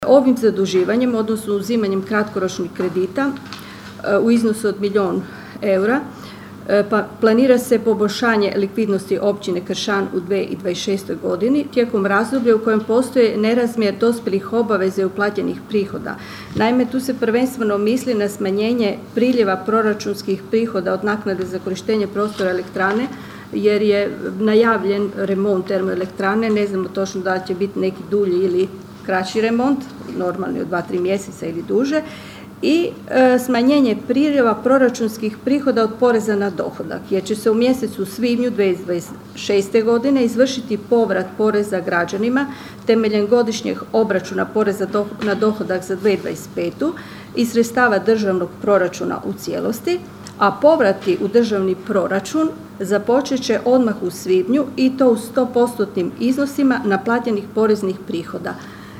Na sinoćnjoj sjednici Općinskog vijeća Kršana vijećnici nisu donijeli odluku o milijun eura revolving kreditu, tražeći detaljniju analizu i obrazloženje.